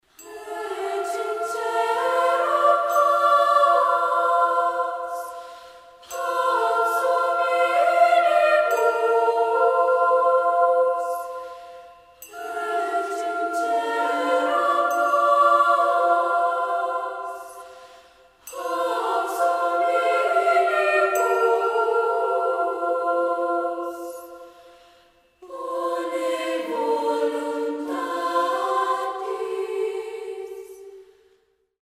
SSAA (4 voices children OR women).
Avant garde.
Mood of the piece: serene ; mystical ; joyous ; mysterious
Soloist(s): Soprano + Alto (2 soloist(s))
Tonality: E lydian ; clusters